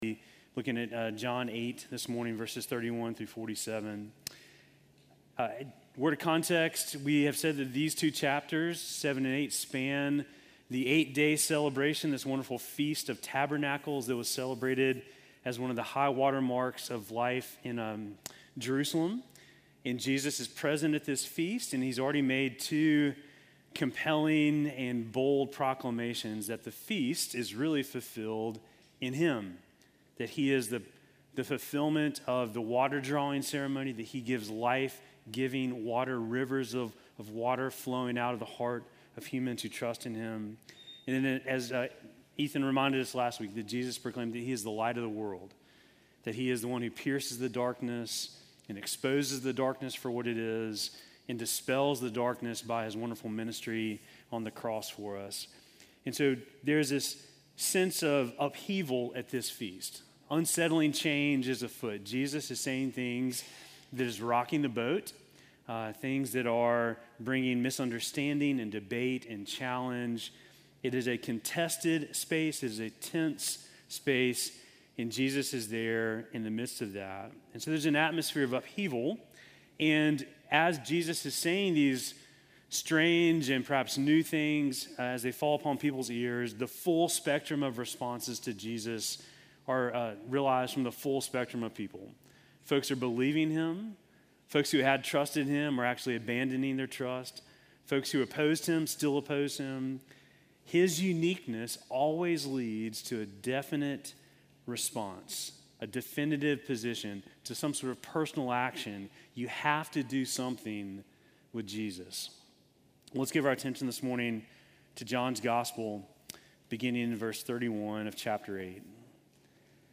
Sermon from September 14